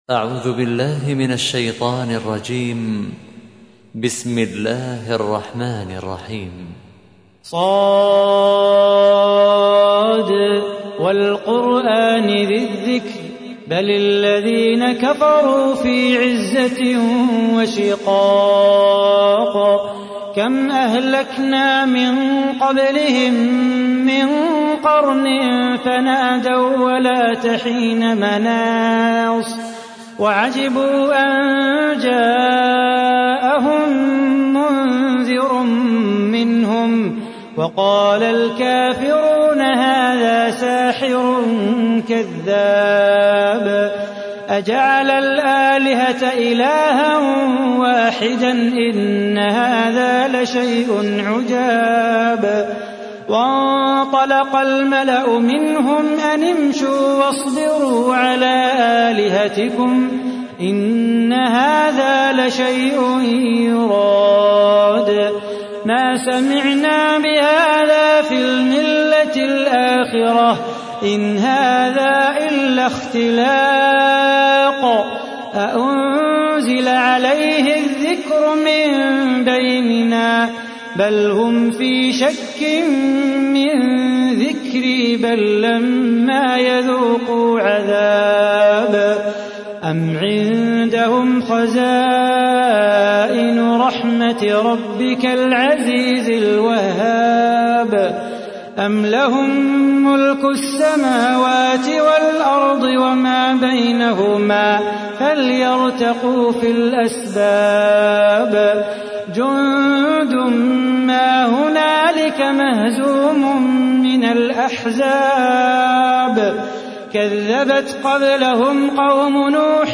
تحميل : 38. سورة ص / القارئ صلاح بو خاطر / القرآن الكريم / موقع يا حسين